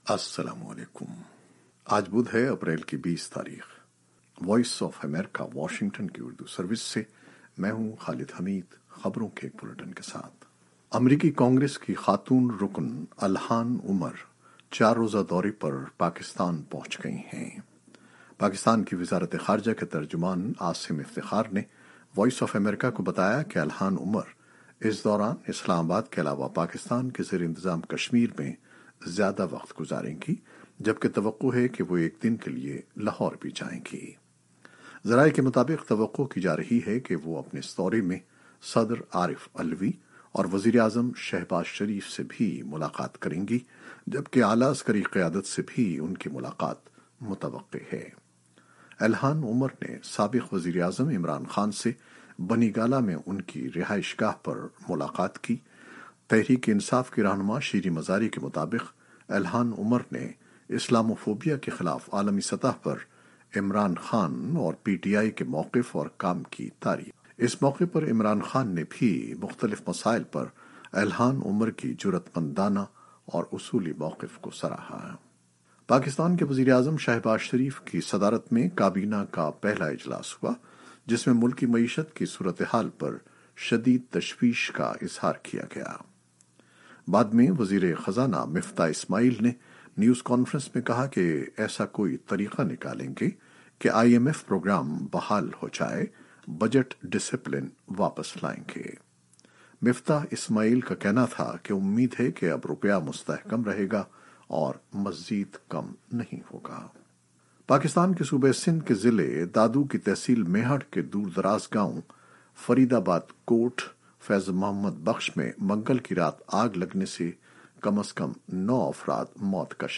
نیوز بلیٹن 2021-20-04